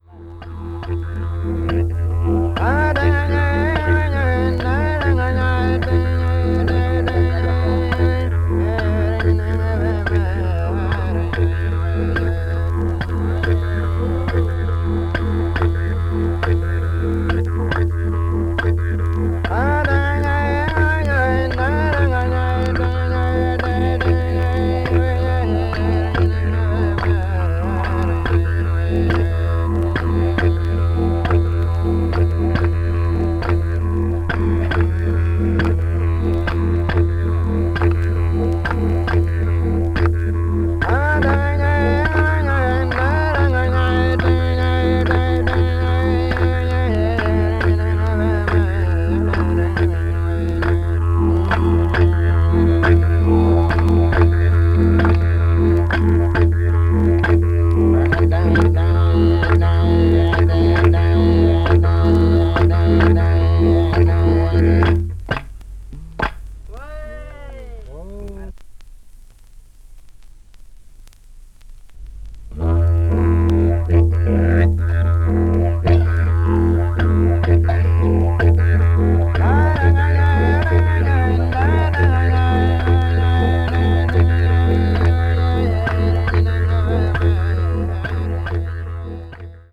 Arnhem Land Popular Classics : Aboriginal Dance Songs with Didjeridu Accompaniment
aborigini   australia   didjeridu   ethnic music   folk   traditional   world music